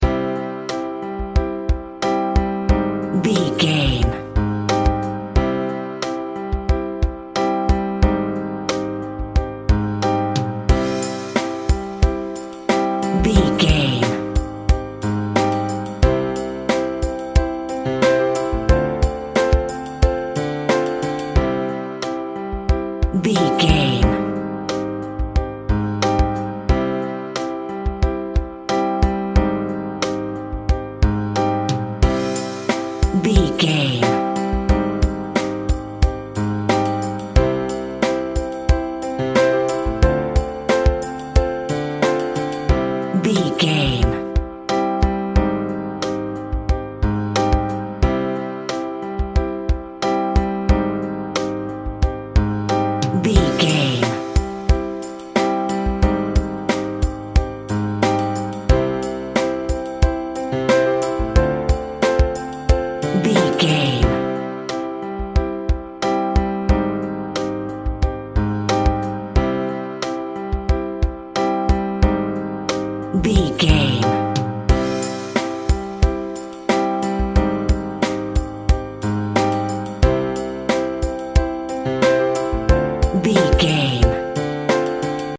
Ionian/Major
dramatic
melodic
cinematic